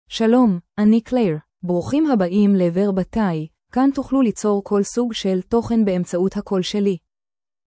FemaleHebrew (Israel)
Claire is a female AI voice for Hebrew (Israel).
Voice sample
Female
Claire delivers clear pronunciation with authentic Israel Hebrew intonation, making your content sound professionally produced.